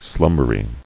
[slum·ber·y]